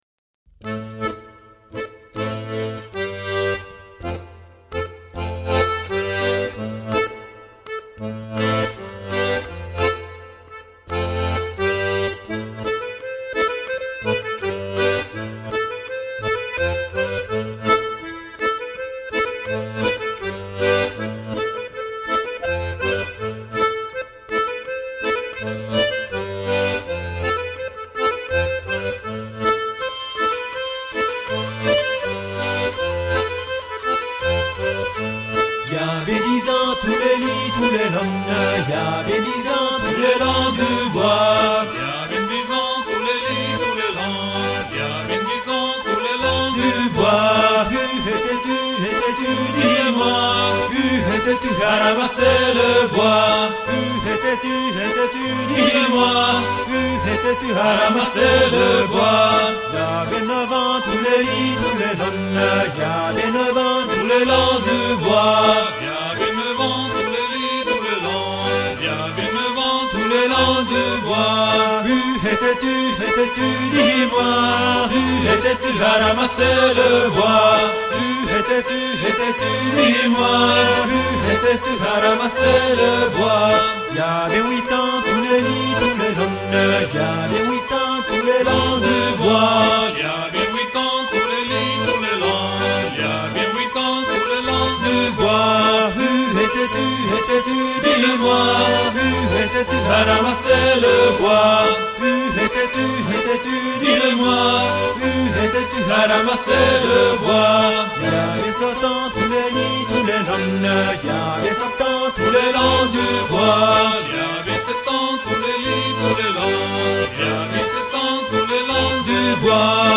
MÚSICAS MEDIOEVALI Y CELTA